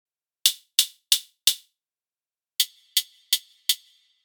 Elektron Gear Model:Cycles
Hats